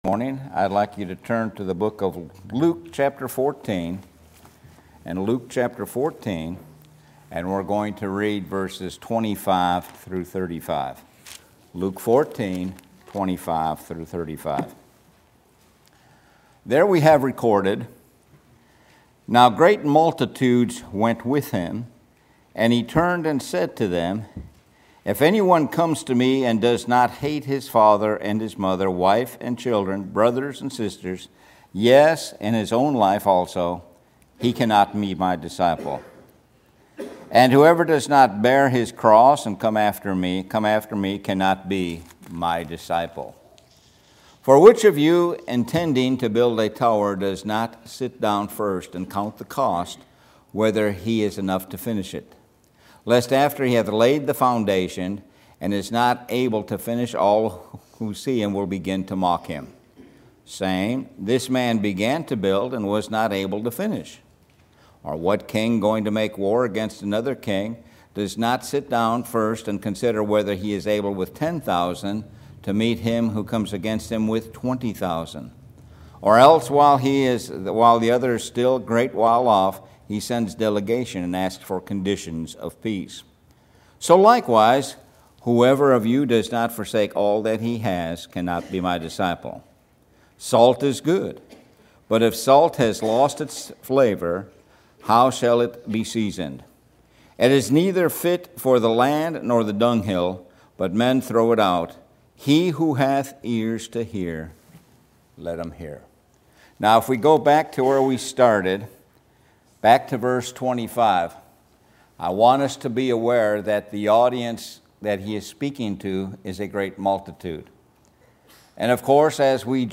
Sun AM Sermon – Being a Disciple